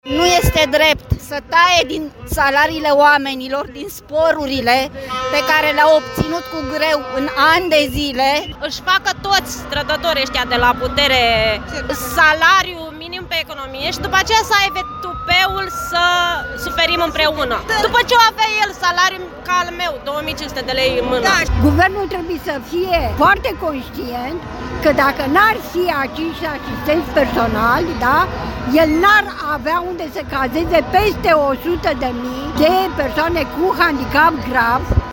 Nemulțumiți de măsurile de austeritate, mii de pădurari, profesori, medici, asistenți sociali și studenți veniți din toată țara, protestează în Piața Victoriei din București.
„Nu este drept să taie din salariile oamenilor, din sporurile pe care le-au obținut cu greu în ani de zile”, a reclamat o femeie.